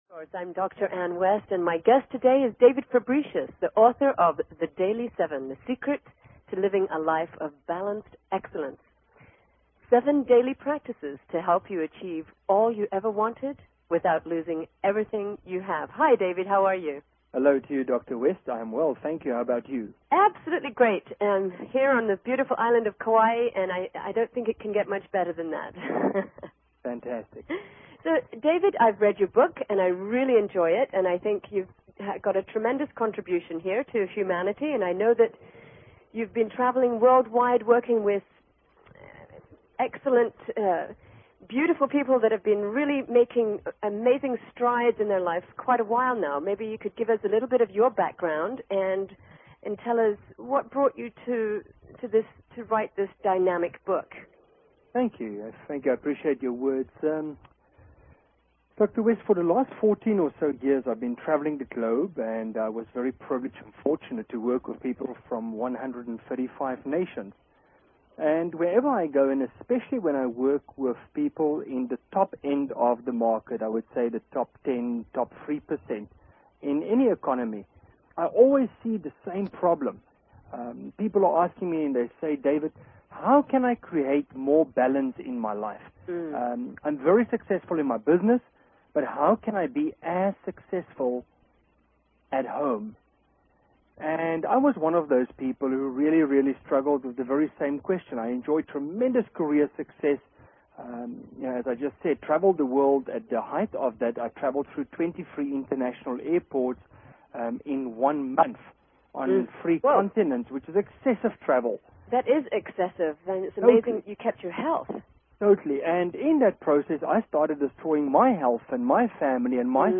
Talk Show Episode, Audio Podcast, Truth_From_Source and Courtesy of BBS Radio on , show guests , about , categorized as